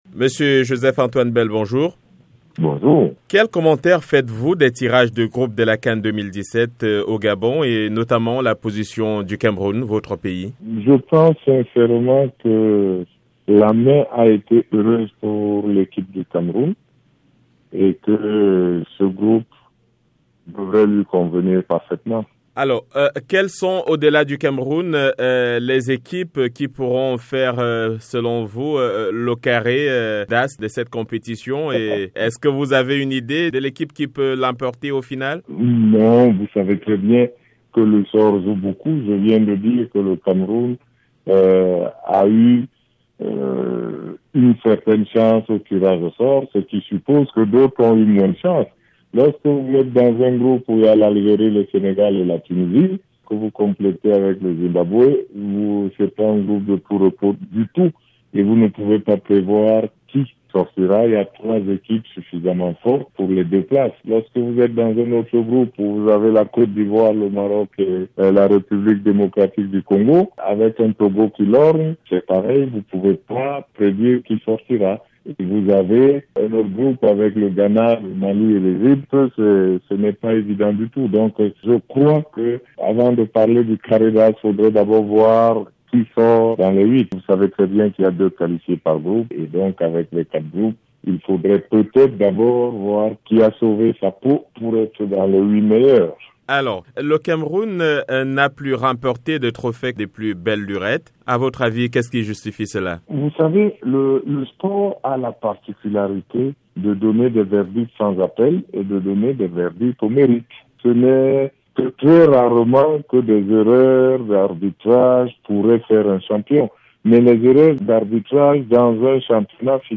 CAN 2017 : Joseph Antoine Bell parle des chances du Cameroun-Interview